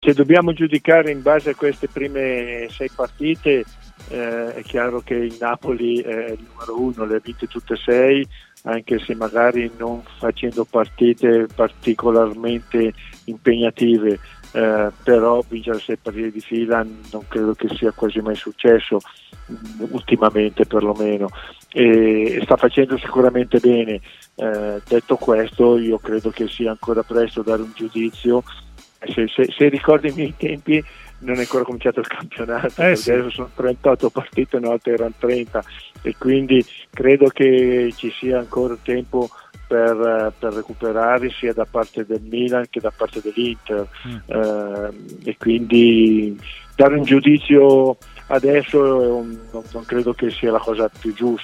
L'ex calciatore e tecnico Alessandro Scanziani è intervenuto a Stadio Aperto, trasmissione pomeridiana di TMW Radio,